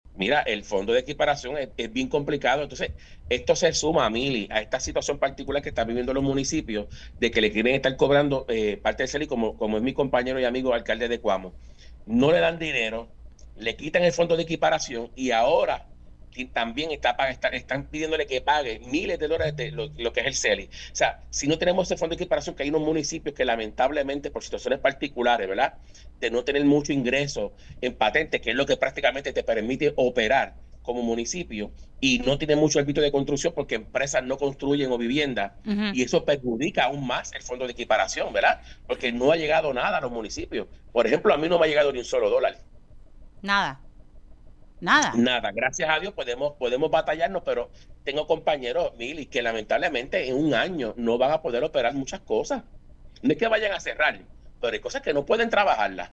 El alcalde de Guayama, Obrain Vázquez, abogó en este medio a favor de una reconsideración a la compañía energética LUMA Energy a pesar de acuerdo sobre contribución en lugar de impuestos (CELI).